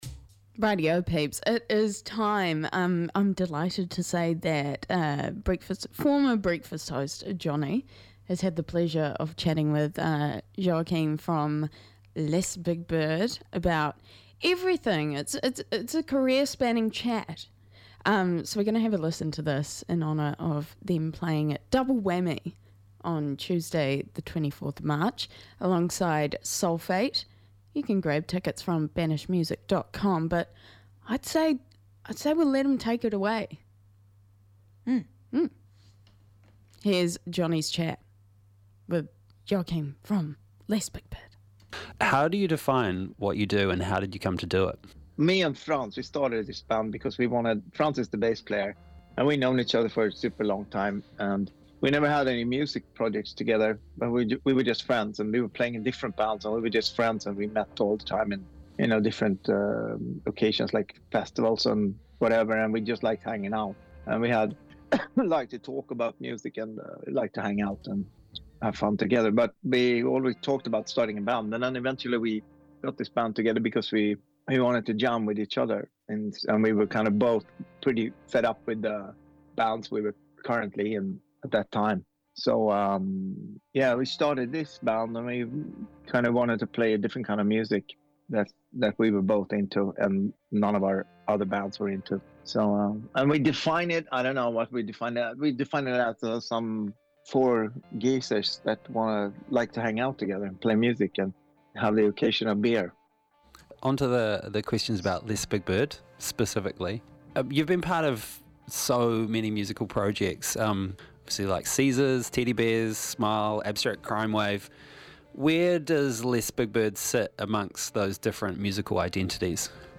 Guest Interview with Les Big Byrd: Rāapa March 11, 2026